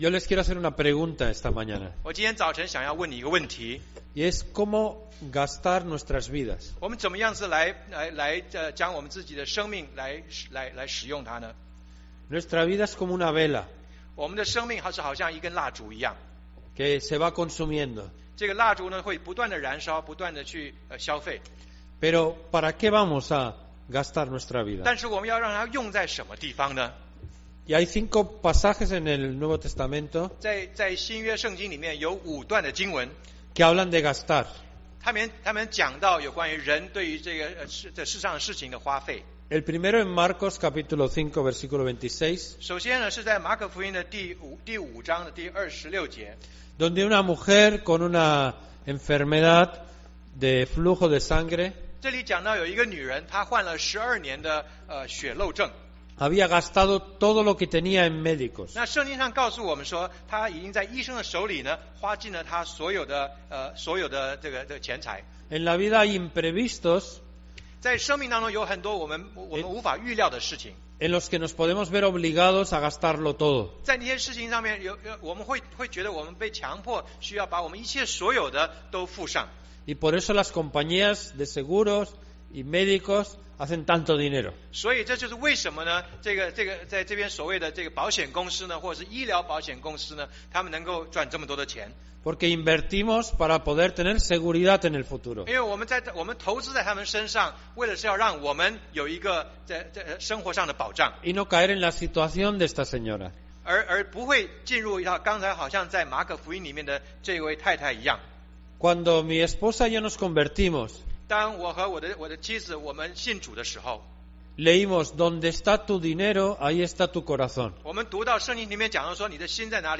中文堂講道信息 | First Baptist Church of Flushing